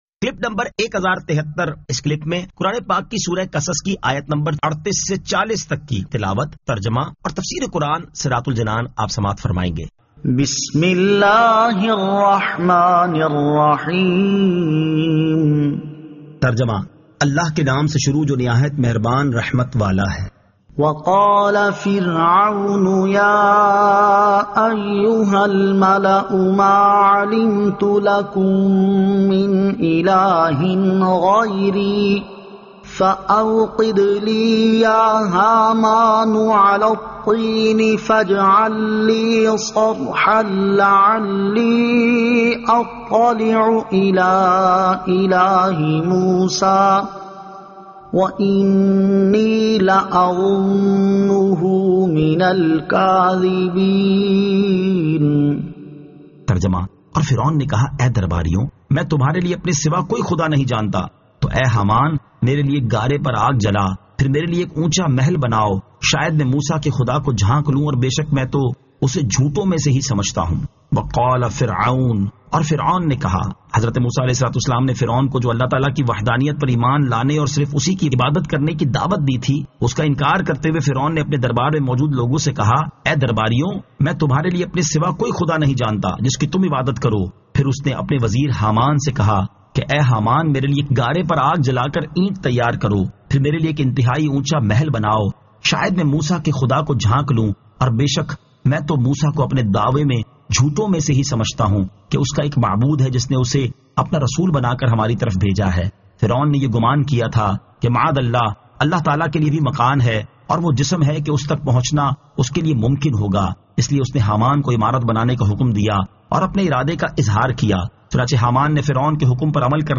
Surah Al-Qasas 38 To 40 Tilawat , Tarjama , Tafseer
2022 MP3 MP4 MP4 Share سُوَّرۃُ الْقَصَصٗ آیت 38 تا 40 تلاوت ، ترجمہ ، تفسیر ۔